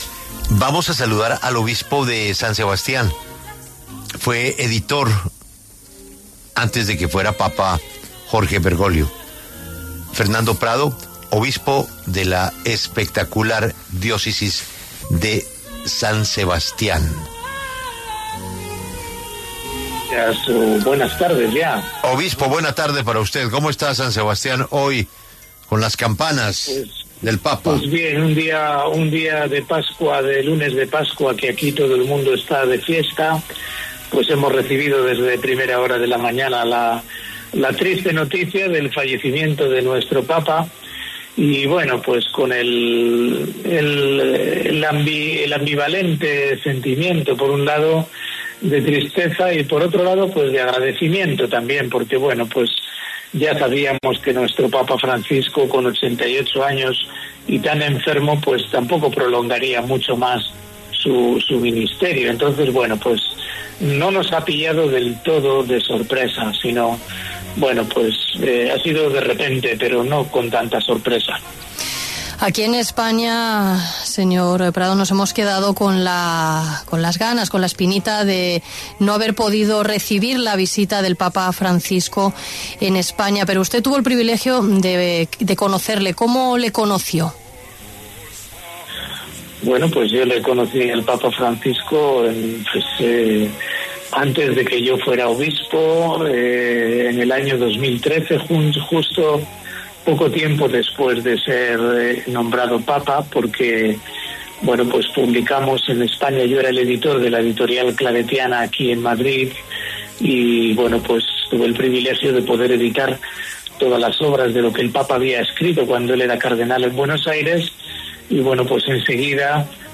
Fernando Prado, obispo de San Sebastián, editor y amigo del papa Francisco, conversó con La W, con Julio Sánchez Cristo, para hablar de la muerte del sumo pontífice a los 88 años.